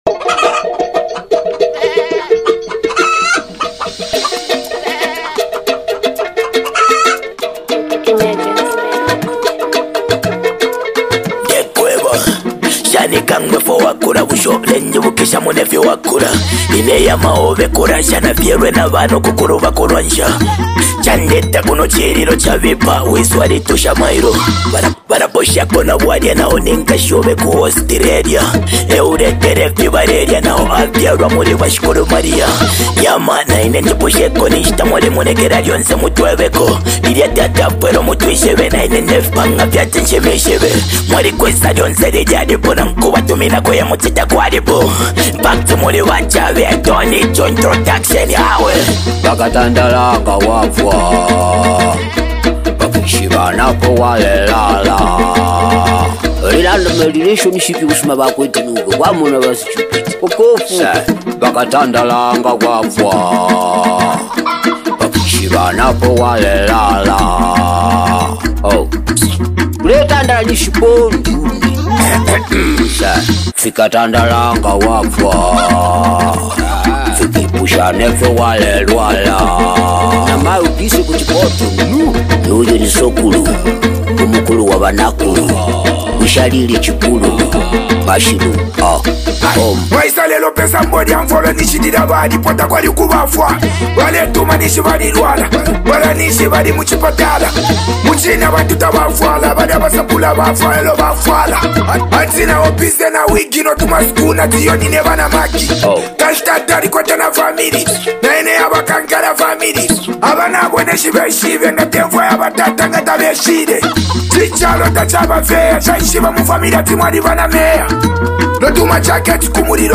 the Zambian duo
signature beats
distinct vocal presence